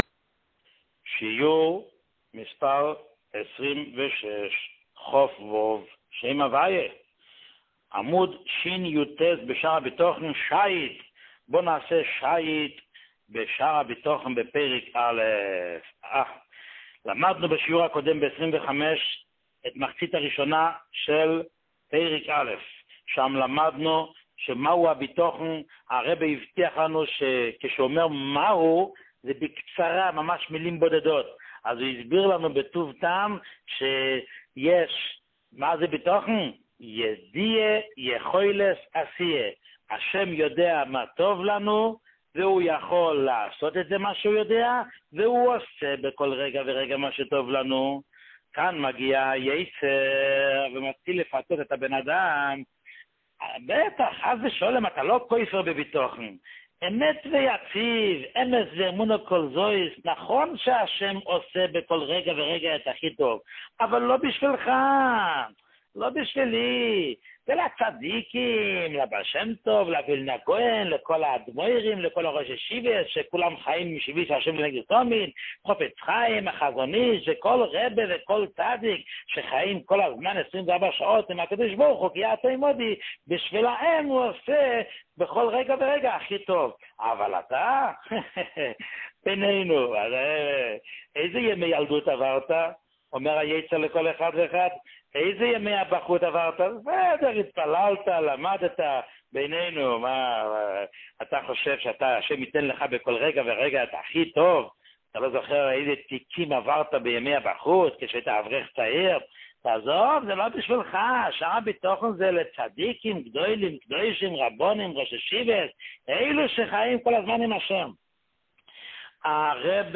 שיעורים מיוחדים
שיעור 26